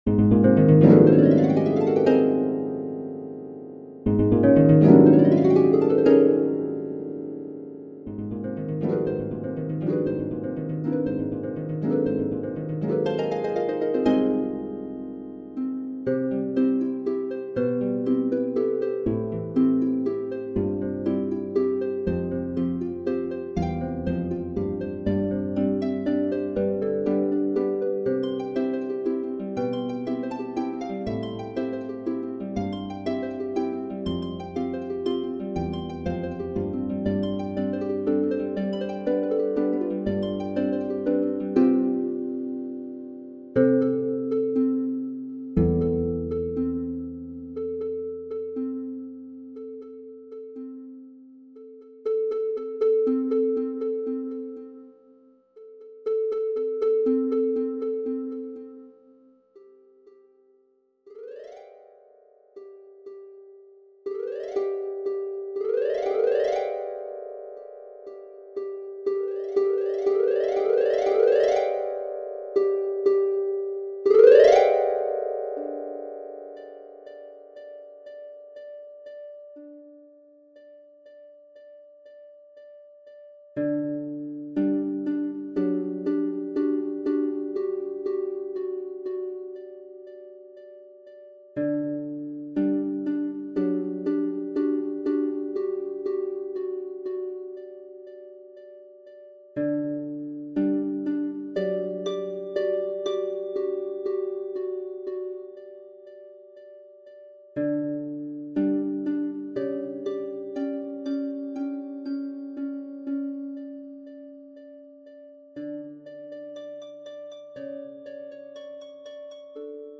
is a whimsical piece for four to five lever or pedal harps